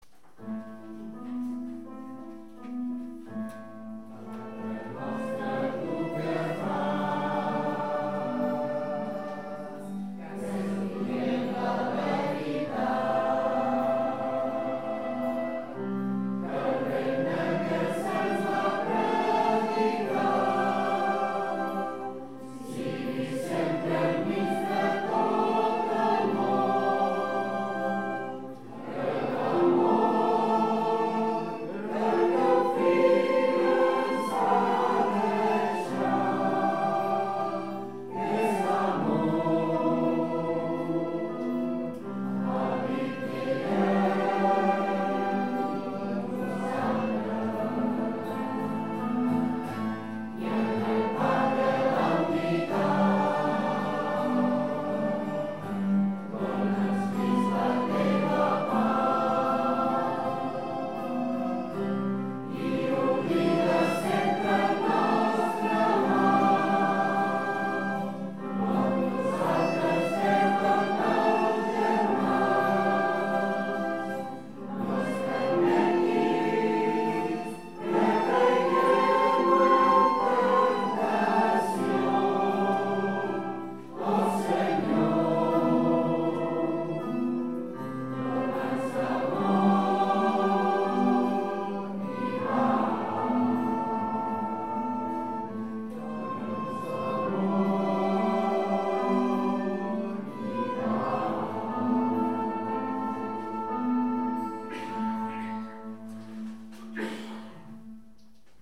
Pregària de Taizé
Capella de les Concepcionistes de Sant Josep - Diumenge 30 de novembre de 2014